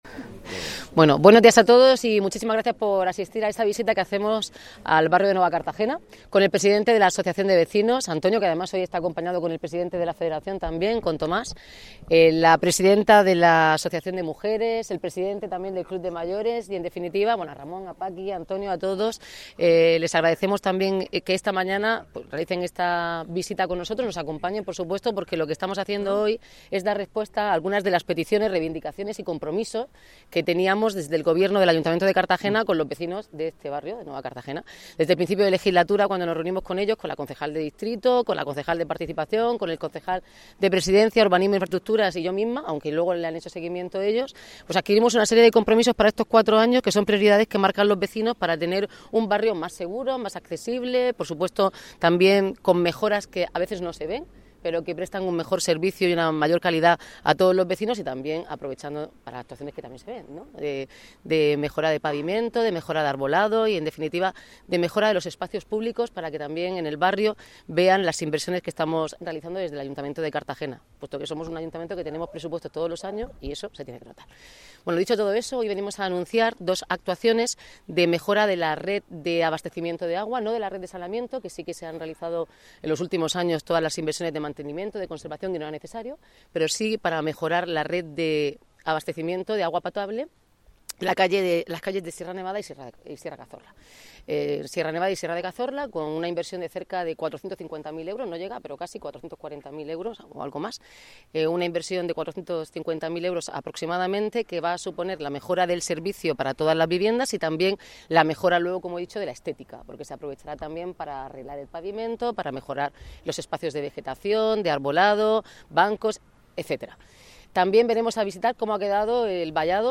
Enlace a Declaraciones de Noelia Arroyo